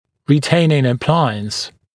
[rɪ’teɪnɪŋ ə’plaɪəns][ри’тэйнин э’плайэнс]ретенционный аппарат